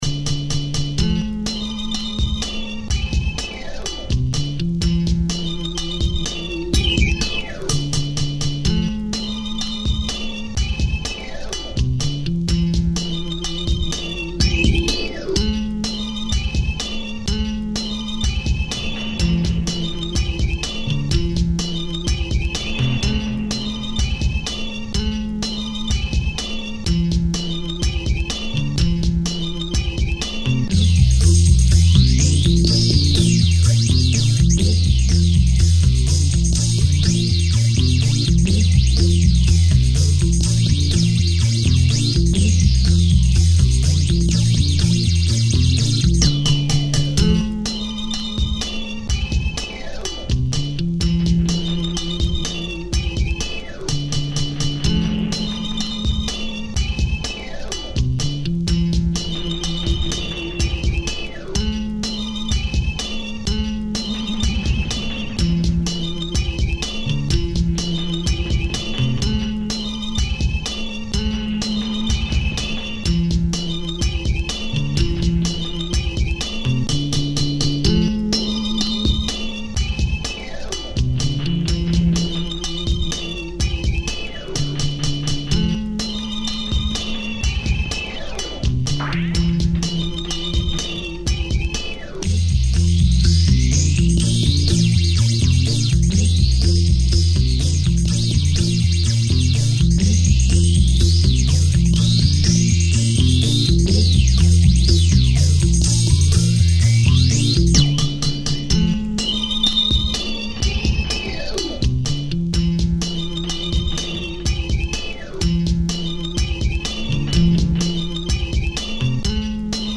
Kind of makes you want to dance, doesn’t it?